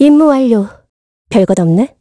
Ripine-Vox_Victory_kr-02.wav